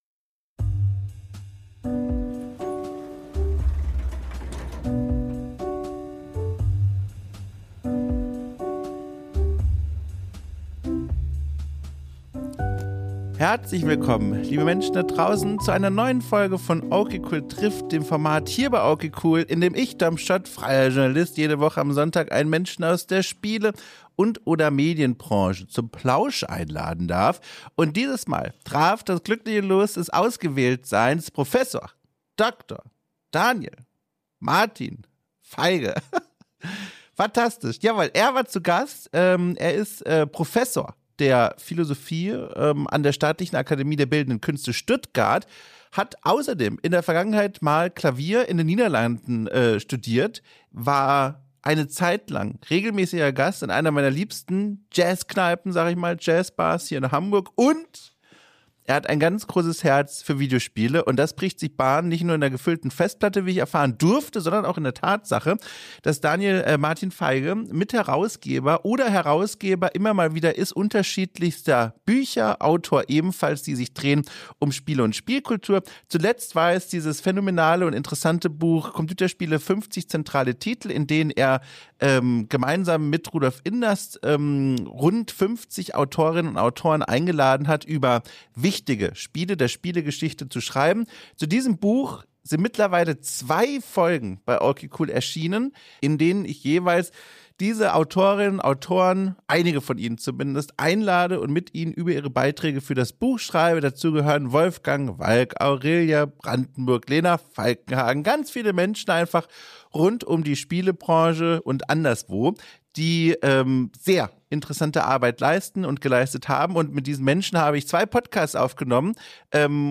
Im Gespräch mit dem Philosophen, der Videospiele spielt
Gespräche über die Arbeit und das Leben drumrum